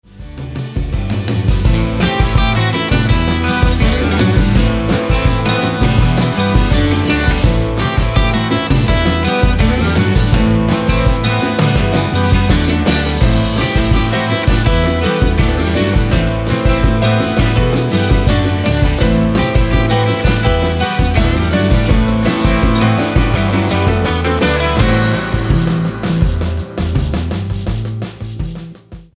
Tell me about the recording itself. Here’s a short clip I deliberately mixed hot. There’s no digital clipping (the maximum sample level is -0.1dBFS,) but there are lots of inter-sample “errors”, including a nasty string of them at 0:13. intersample-peak-crossroads-clipped.mp3